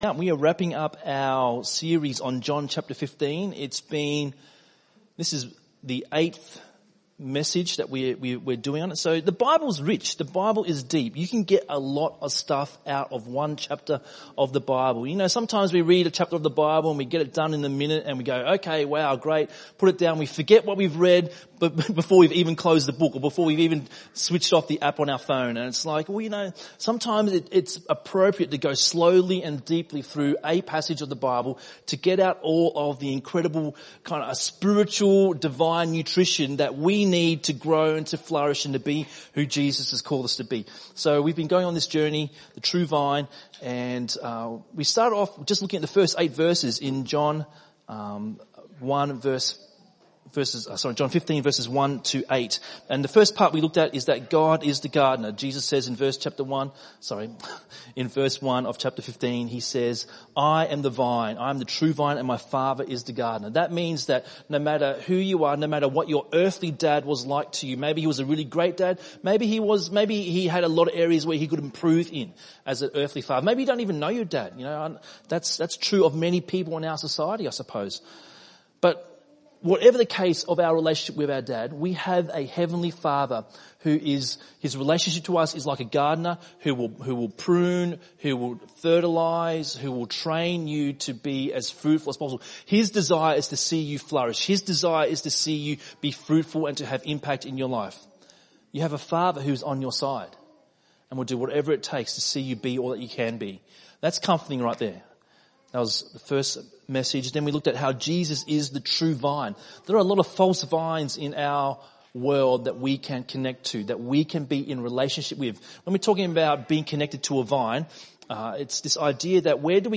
ENM Sermon